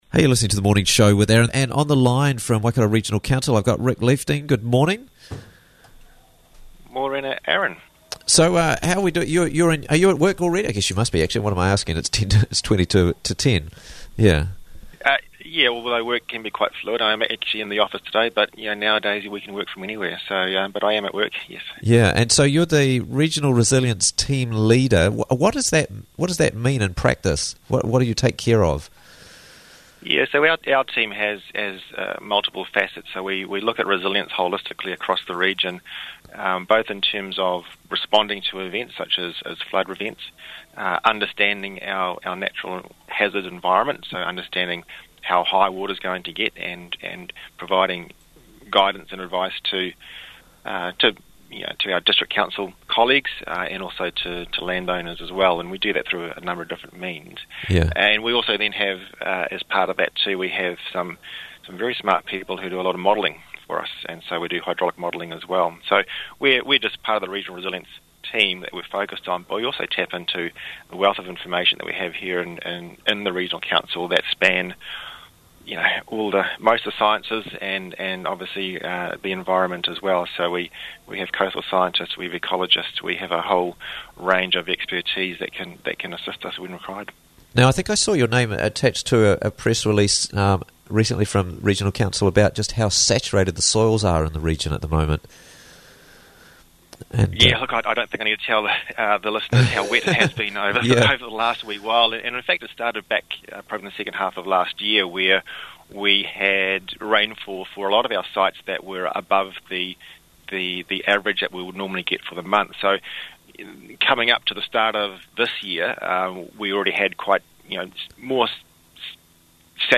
Coastal Erosion & Extreme Weather Events - Interviews from the Raglan Morning Show